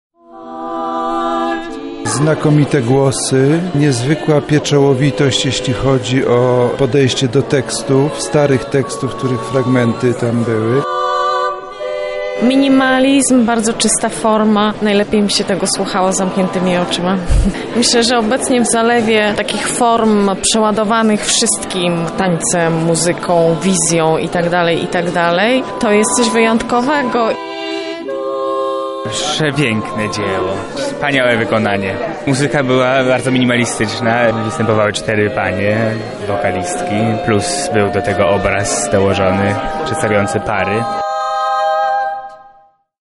Twórcy przekazali ją poprzez 12 tekstów różnych autorów oraz głosy czterech śpiewaczek.